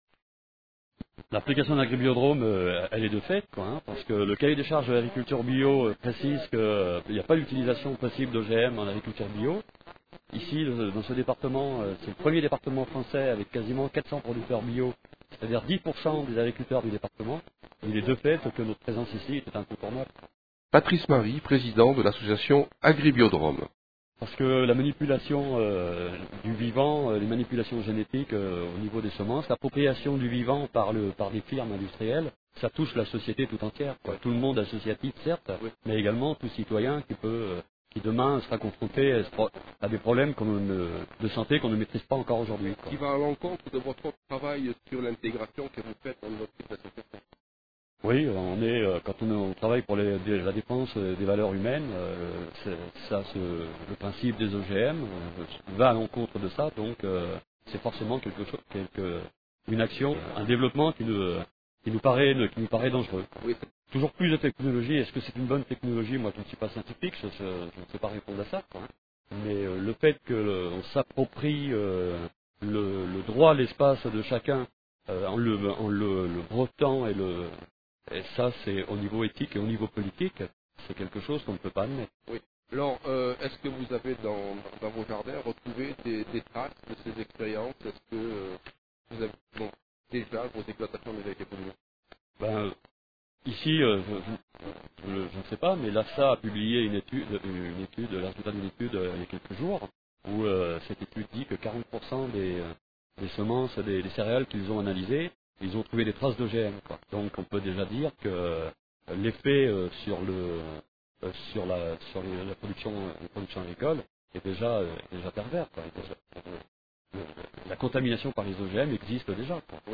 Les Interviews de Radio-Méga
le 26 Août 2001 à Salette